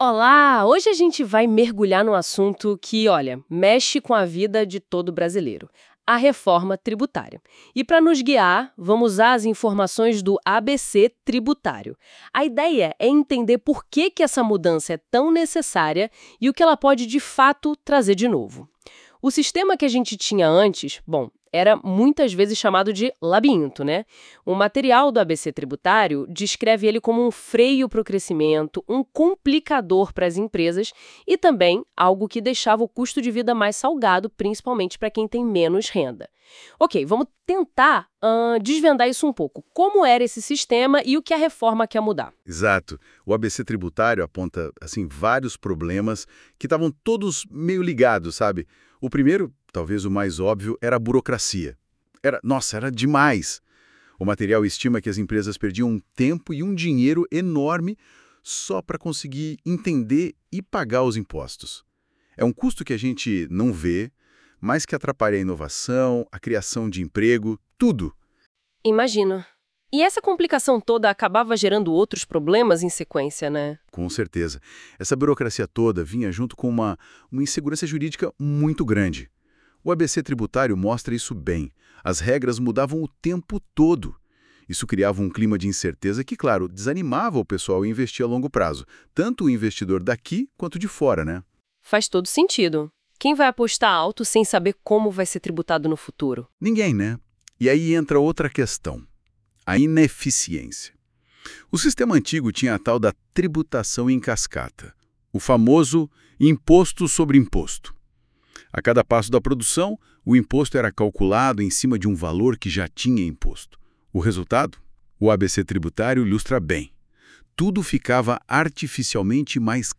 Junte-se aos nossos dois apresentadores e forme o Trio Tributário para um resumo esclarecedor sobre o Por Que a Reforma Tributária é Importante?